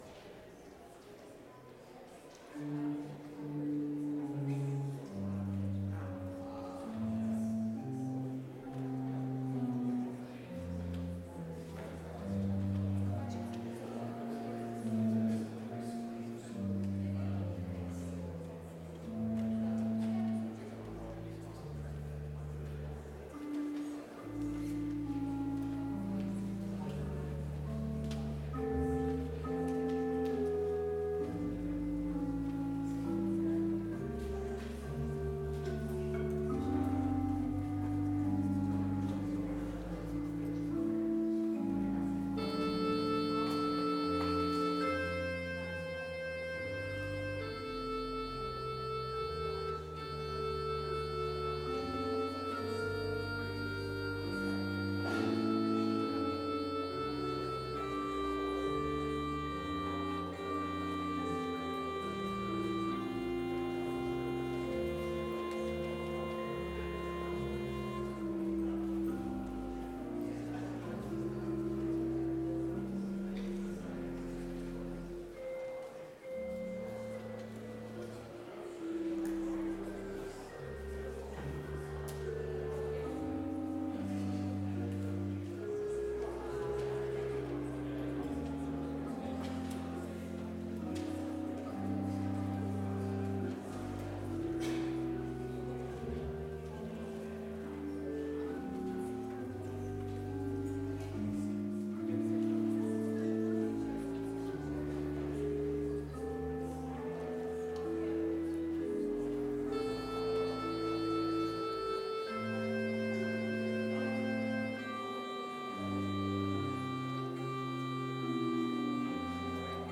Complete service audio for Chapel - January 19, 2023